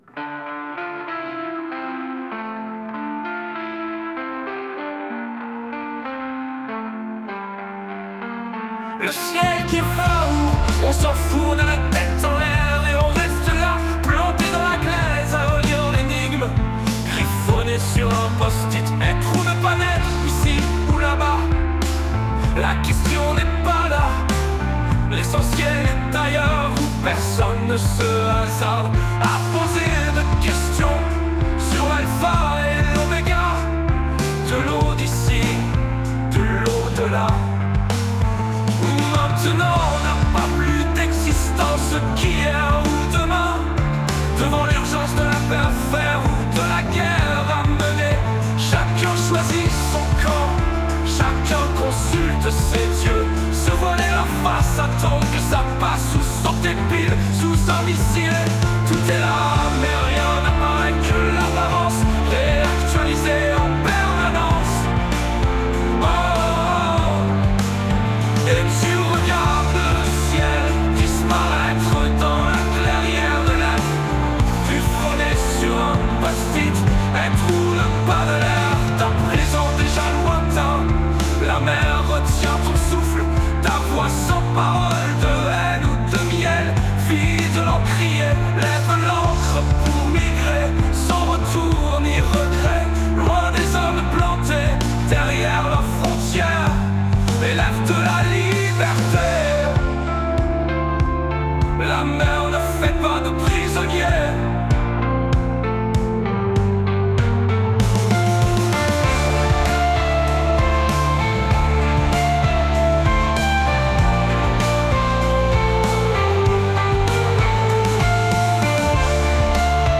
Le-ciel-qui-va-ou-dark-rock-beats-post-punk.mp3